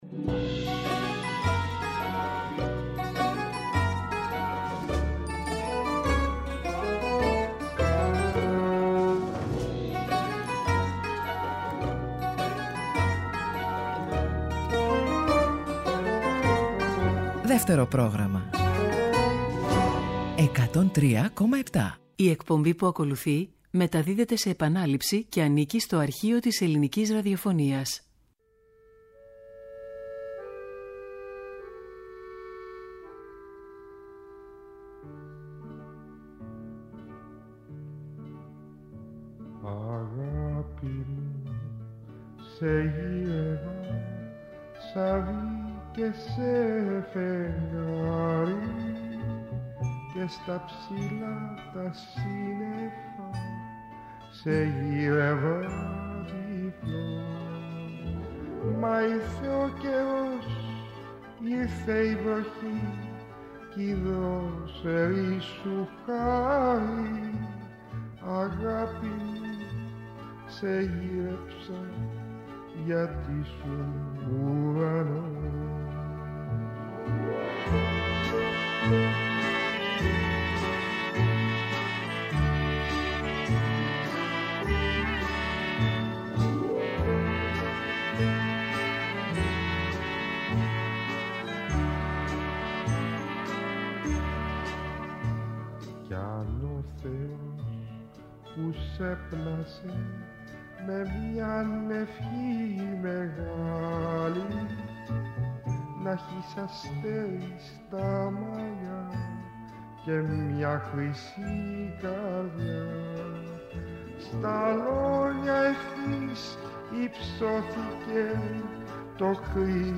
ακούγονται με τη φωνή του μέσα από το ραδιοφωνικό Αρχείο.